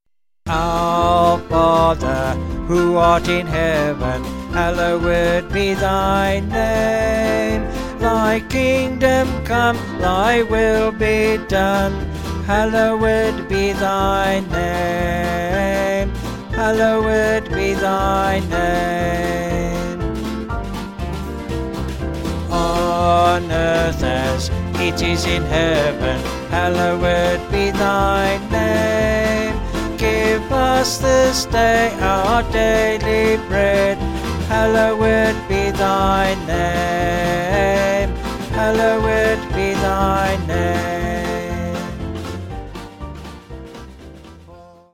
Vocals and Band
Author: Traditional Caribbean